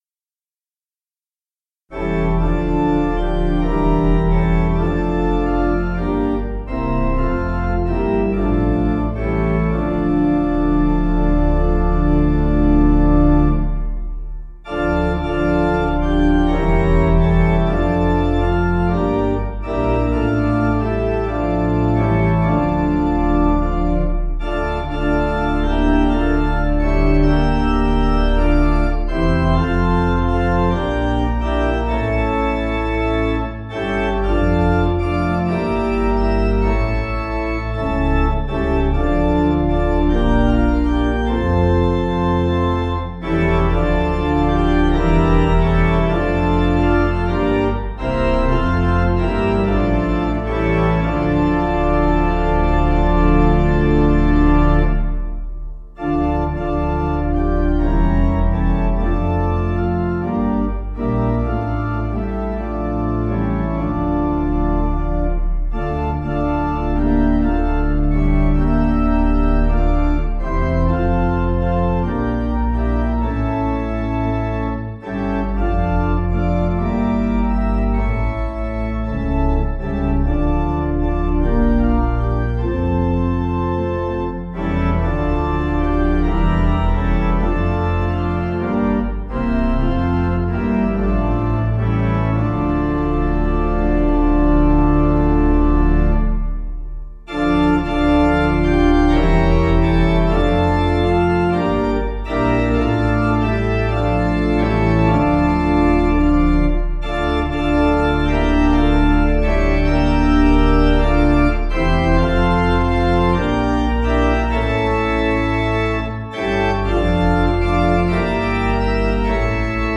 Danish Melody
Organ